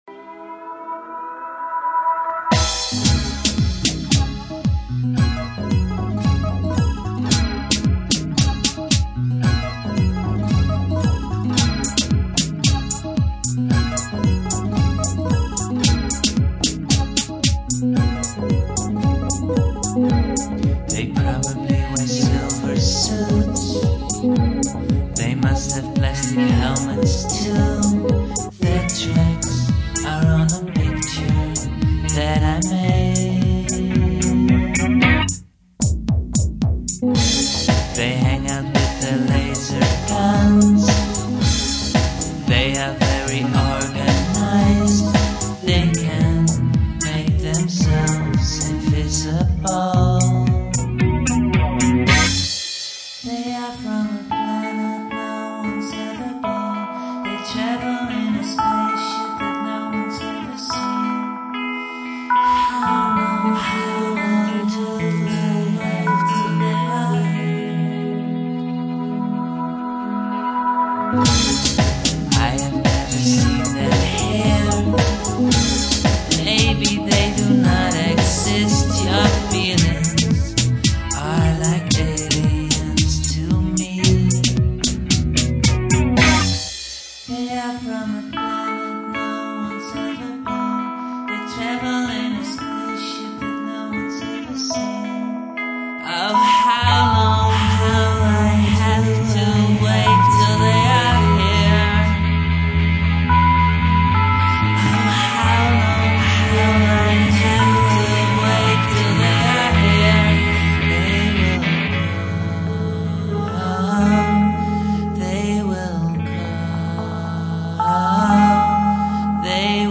where: Studio Aluna , Amsterdam